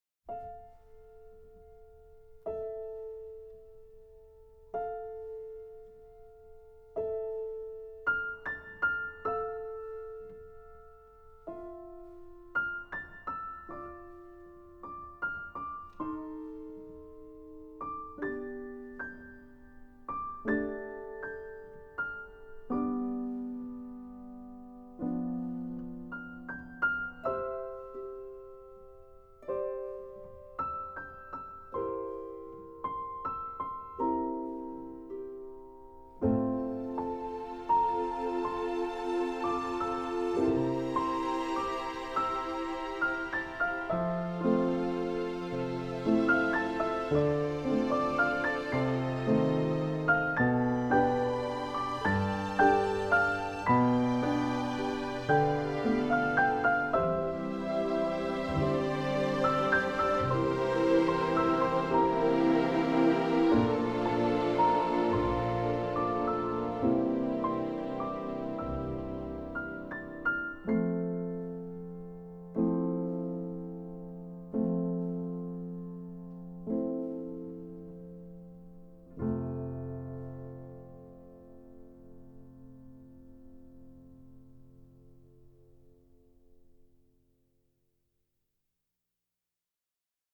موسیقی بی کلام